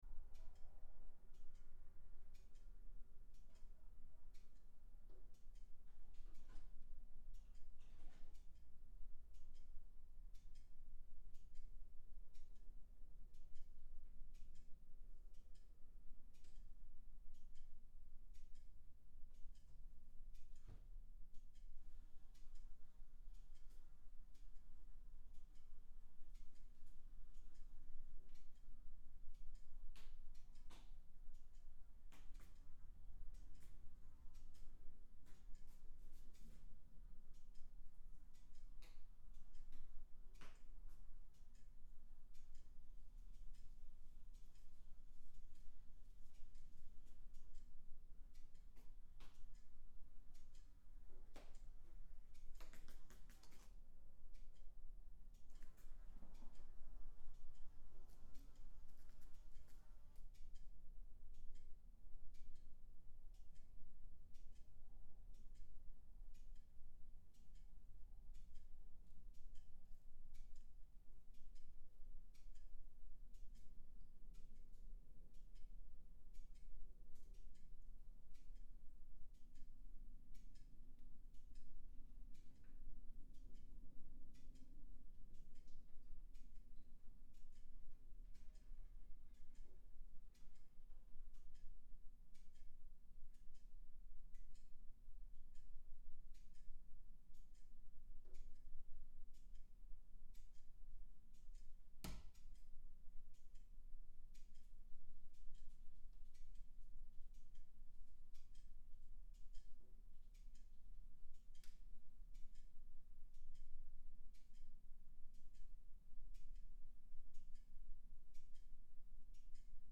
This recordings was made in 50m2 garage in the countryside. This recording contains mainly two ticking clocks, both sides of the mic rig, also a buzzing fly and a mouse jumping somewhere in the garage. Outside is a traffic in a distance
First three audio samples are straight from the recorder at 50dB gain, so it sounds in very low level. *
Rode NT1